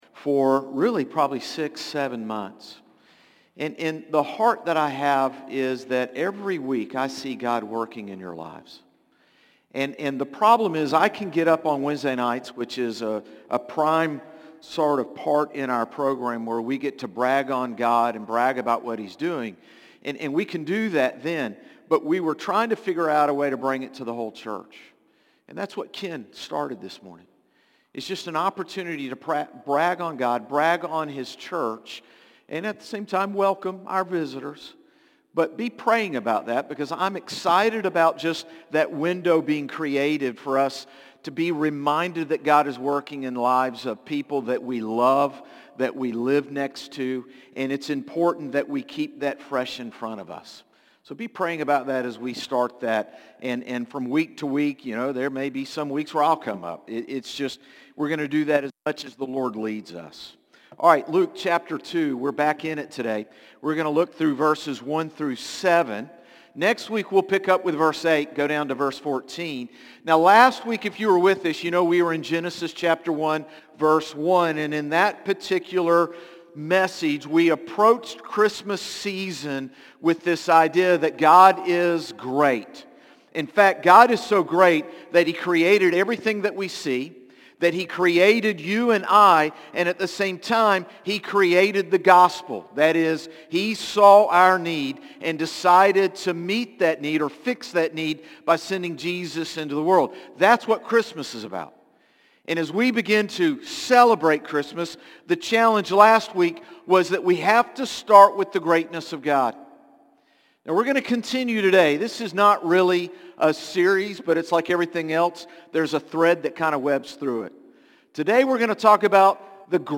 Sermons - Concord Baptist Church
Morning-Service-12-7-25.mp3